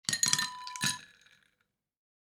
Ice Cube Drop Wav Sound Effect #4
Description: The sound of ice cubes dropping into a glass (filled with liquid)
Properties: 48.000 kHz 24-bit Stereo
A beep sound is embedded in the audio preview file but it is not present in the high resolution downloadable wav file.
ice-cube-drop-preview-4.mp3